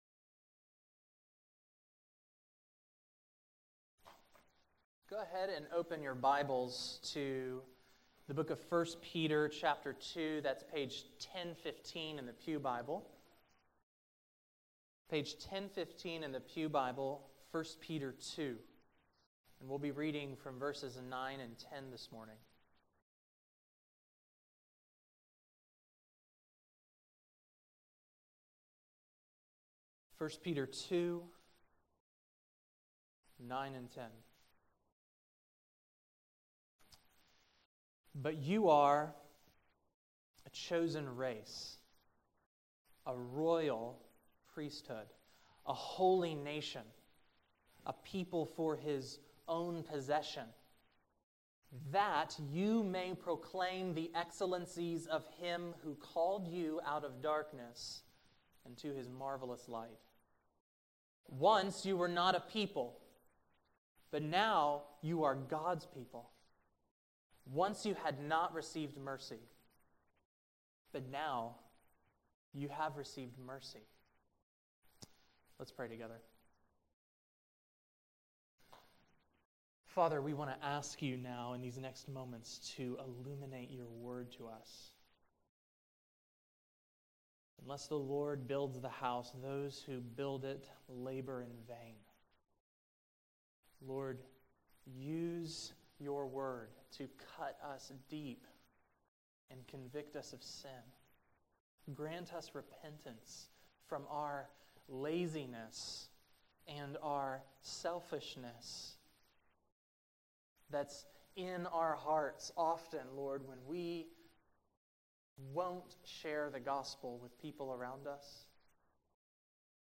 September 4, 2016 Morning Worship | Vine Street Baptist Church